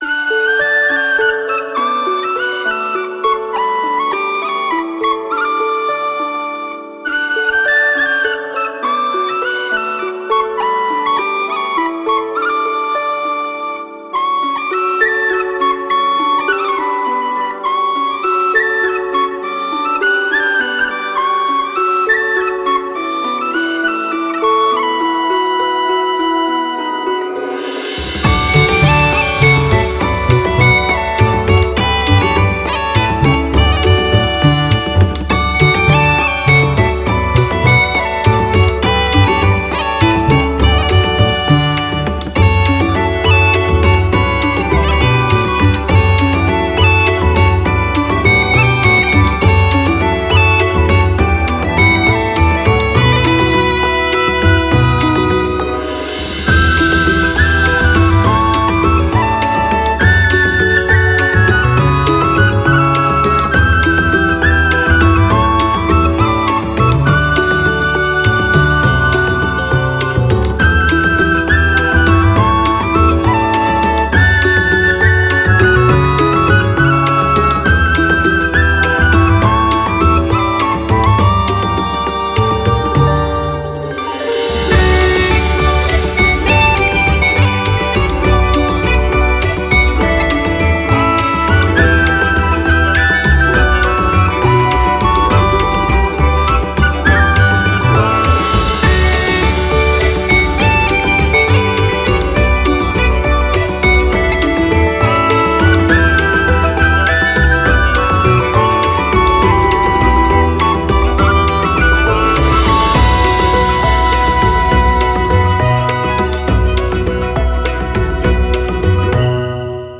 🎻ＢＧＭをご用意しました。